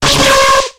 Cri de Migalos dans Pokémon X et Y.